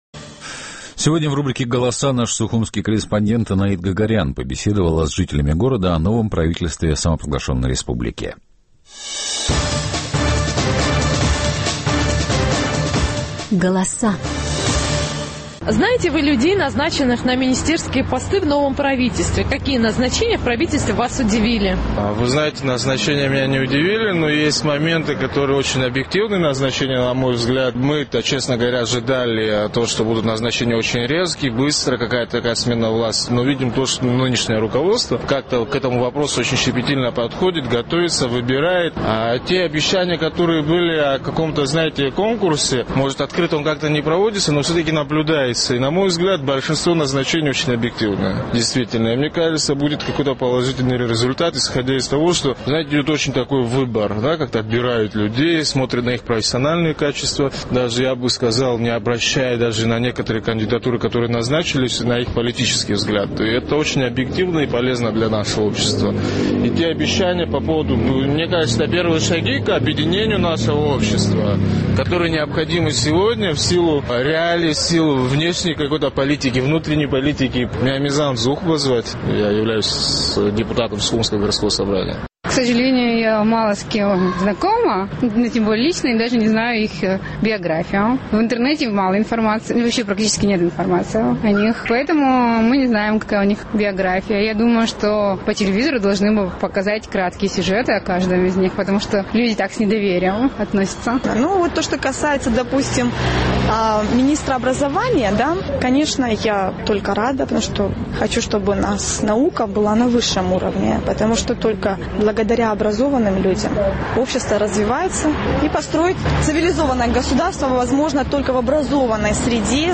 Наш сухумский корреспондент интересовалась мнением жителей абхазской столицы о новом составе правительства республики.